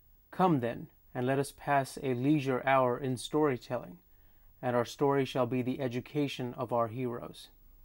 In this lesson we’ll record a piece of audio directly to the Sony a7R II, and then to the Tascam DR-60D recorder.
*No processing has been done. The hiss is from the system itself, probably the microphone (an Audio Technica 3350T lavalier microphone). The camera mic level was at 10.
a7R2Mic.wav